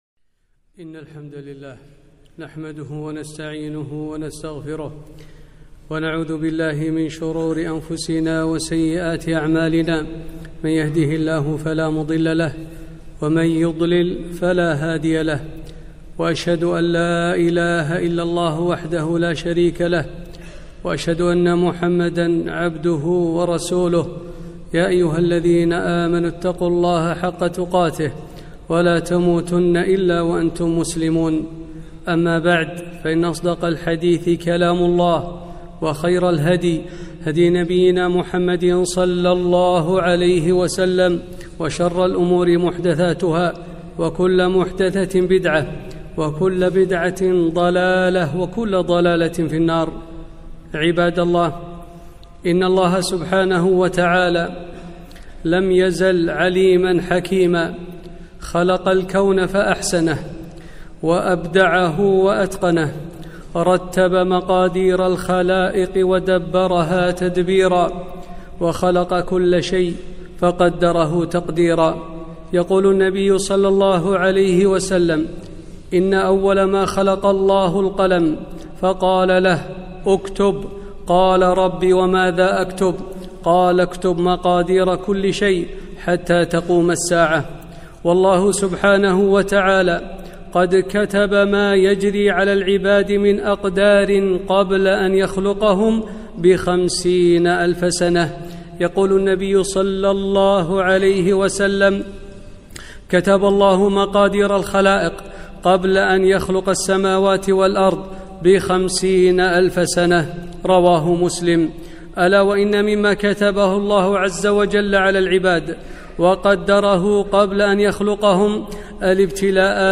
خطبة - ألصبر على أقدار الله المؤلمه